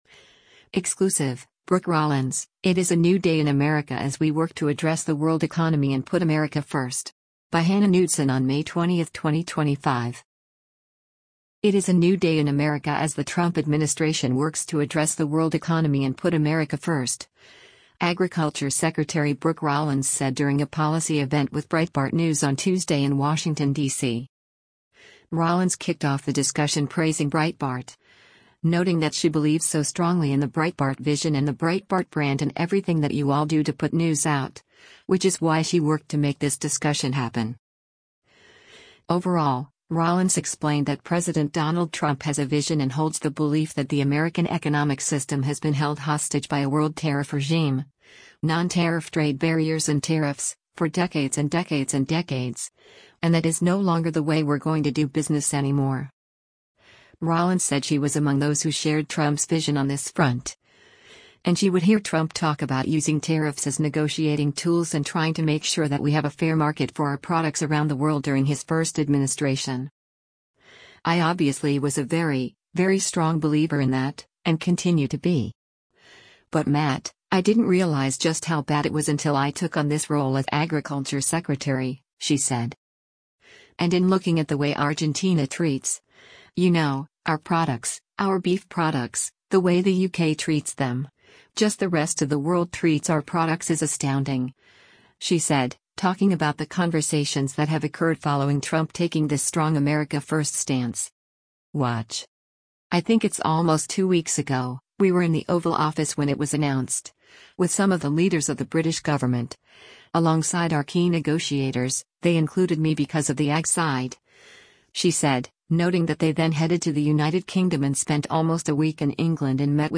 It is a “new day” in America as the Trump administration works to address the world economy and “put America first,” Agriculture Secretary Brooke Rollins said during a policy event with Breitbart News on Tuesday in Washington, D.C.